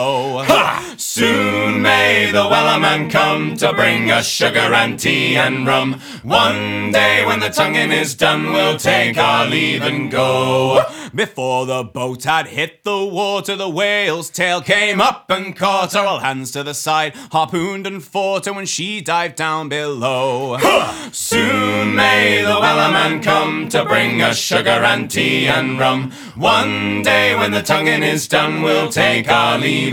• Alternative Folk